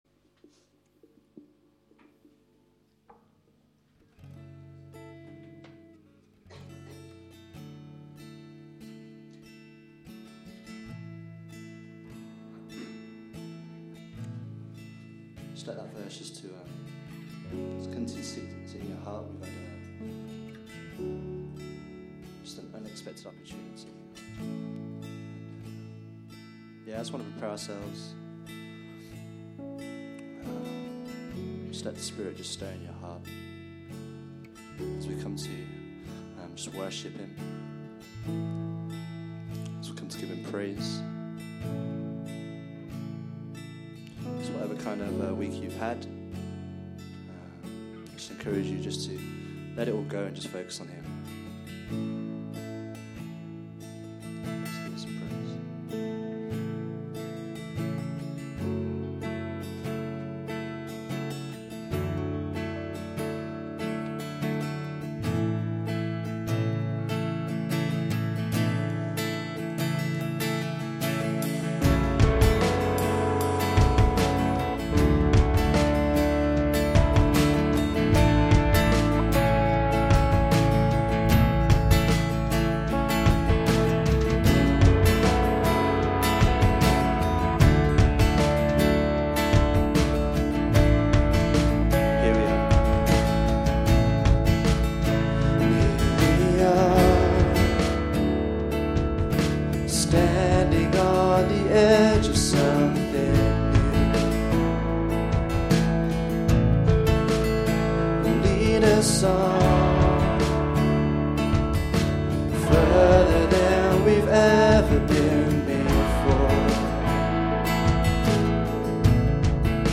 Worship March 6, 2016